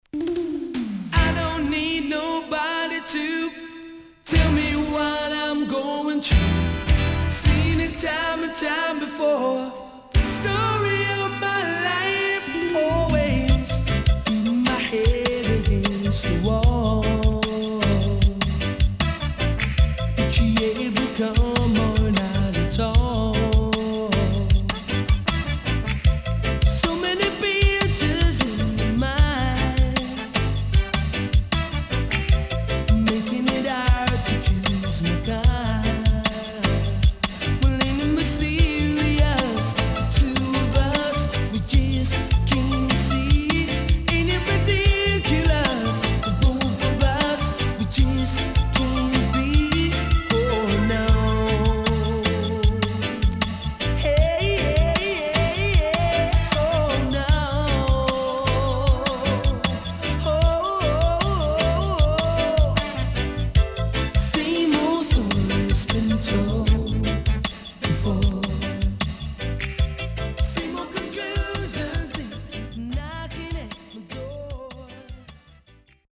SIDE A:1,2曲目、所々チリノイズがあり、少しプチノイズ入ります。